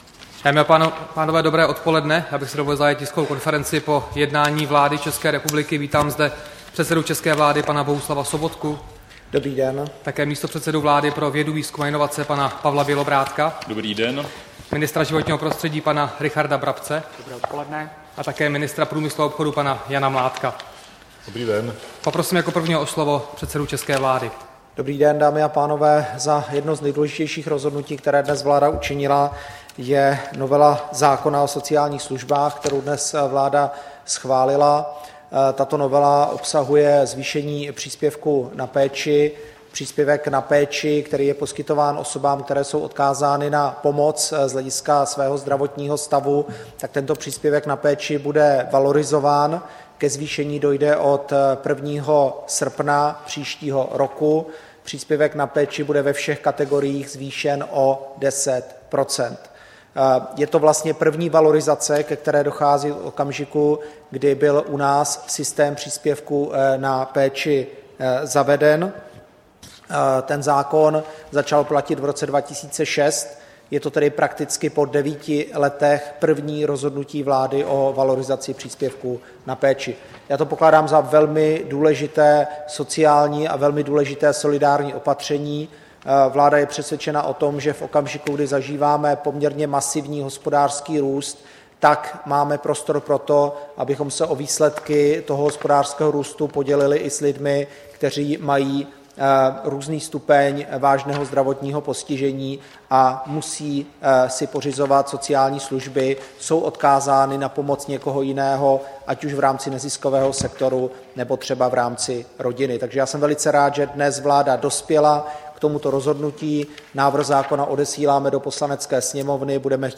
Tisková konference po jednání vlády, 14. prosince 2015